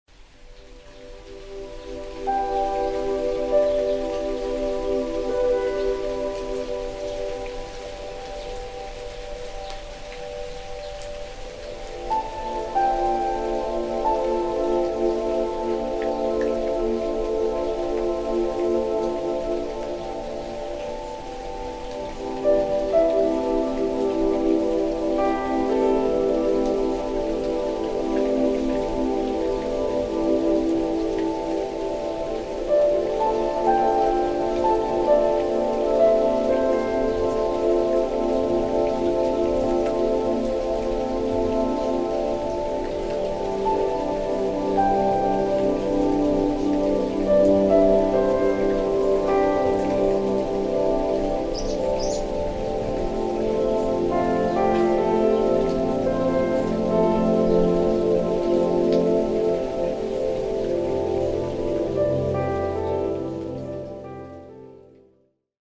לאהוב את עצמך עם מוזיקה וקולות גשם